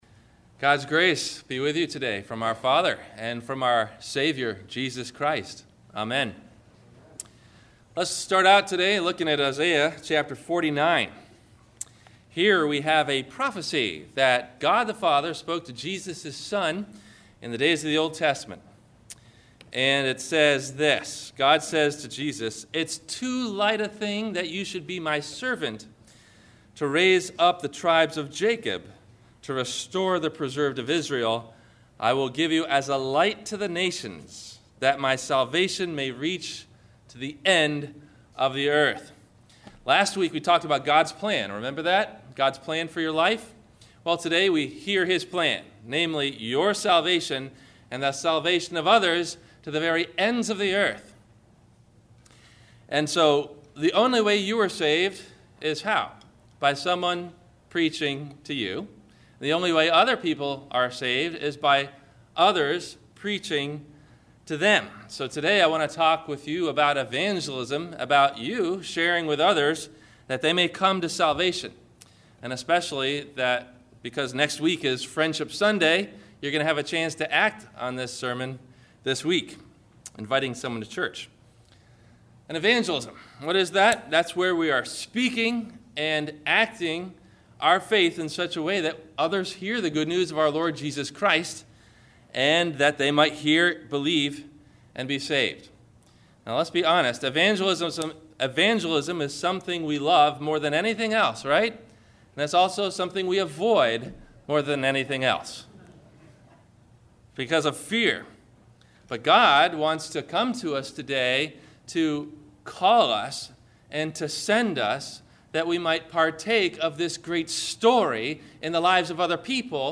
Will God Use Even Me ? – Evangelism – Sermon – February 05 2012